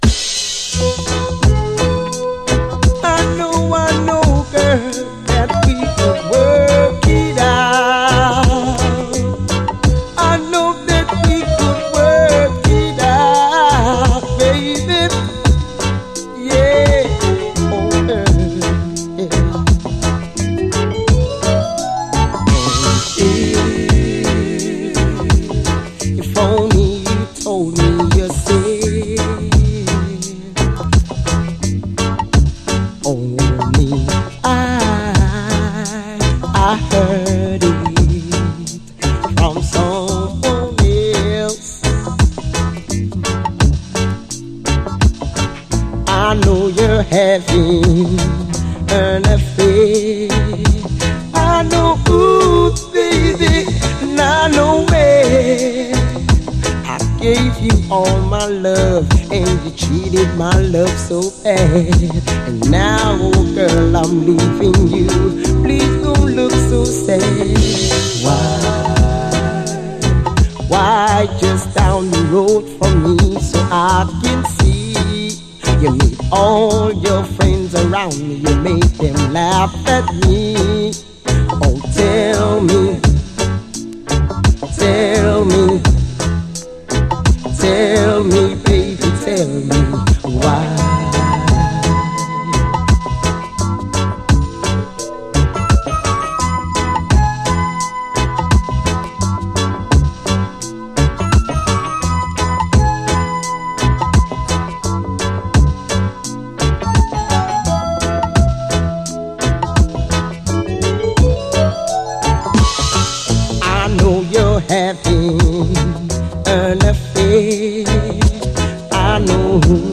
REGGAE
の珍しい男性シンガーによるカヴァー・ヴァージョン！
聴きなれたこの曲が男性ヴォーカルで歌われるのがなんとも新鮮な感覚！